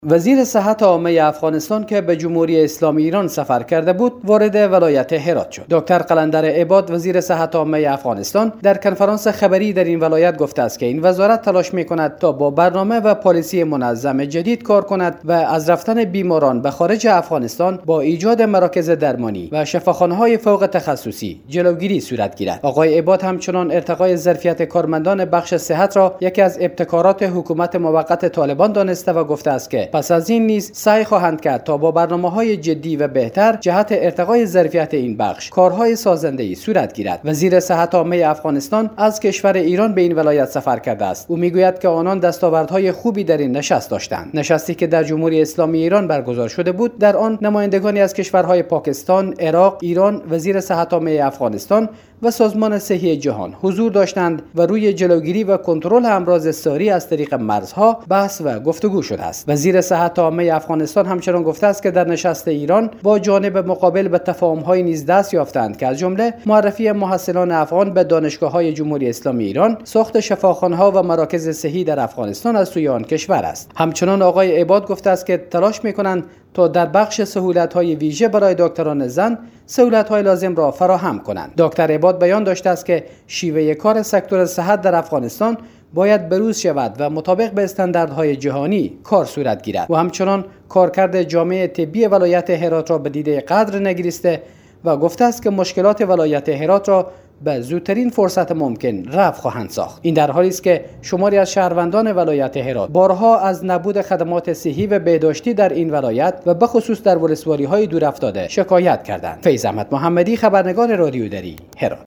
وی در یک کنفرانس خبری در شهر هرات به تشریح دستاوردهای سفر خویش به ایران پرداخت.